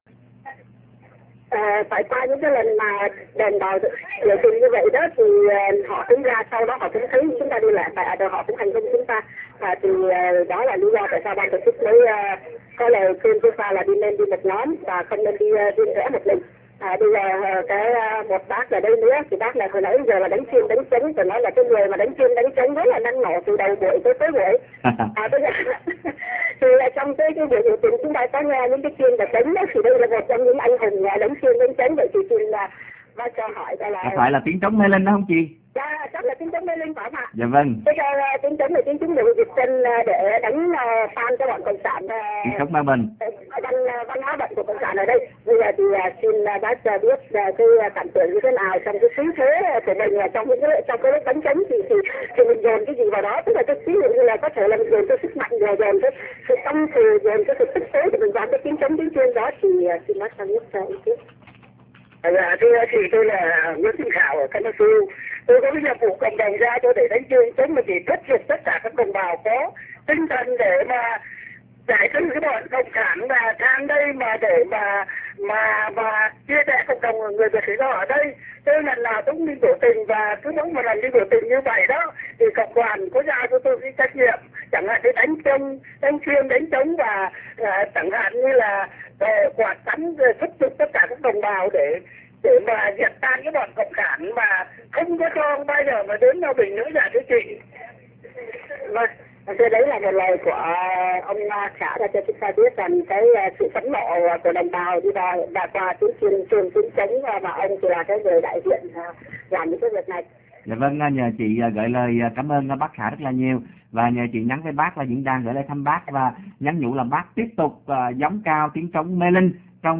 Xin qu� vị chờ trong gi�y l�t sẽ nghe được �m thanh từ cuộc biểu t�nh (phần 4)